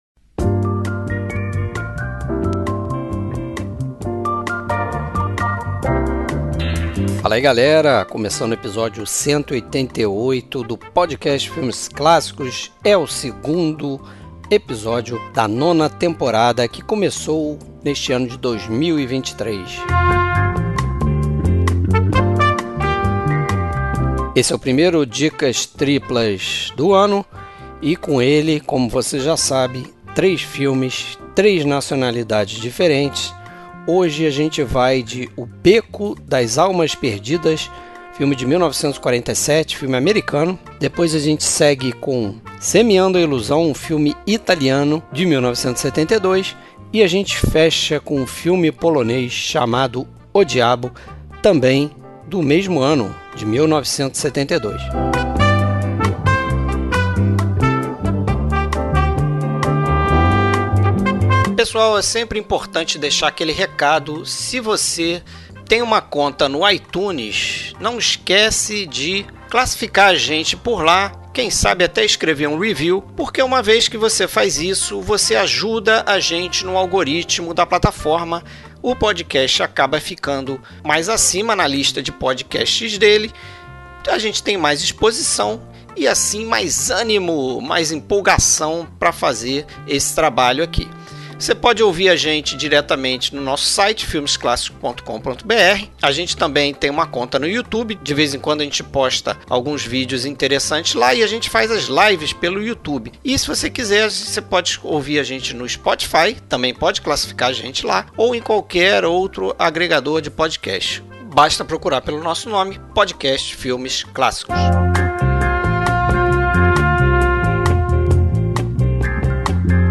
Trilha Sonora: Trilhas sonoras dos filmes comentados neste episódio e outras dos mesmos compositores.